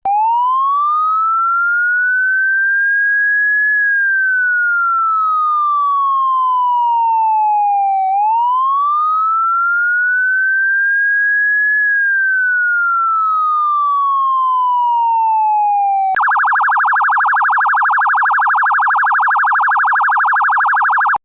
Police Emergency Sirens